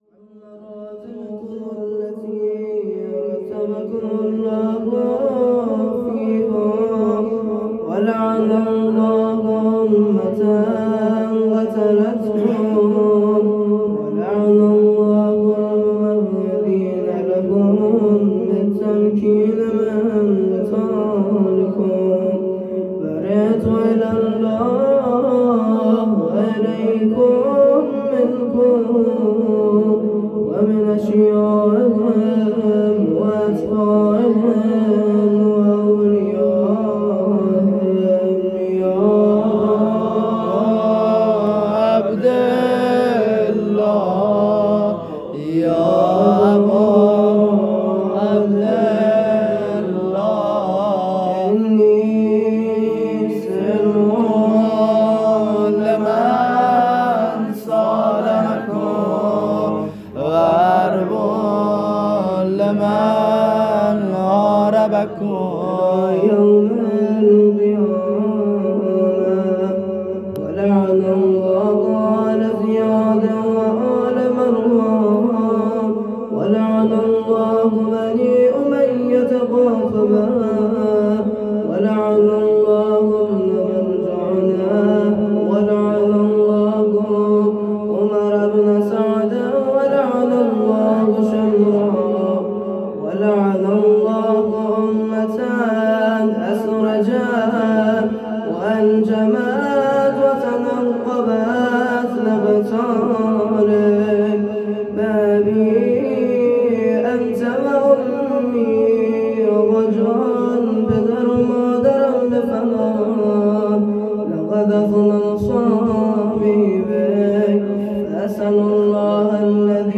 ایام فاطمیه ۱۹ آذرماه ۱۴۰۳ | زیارت عاشورا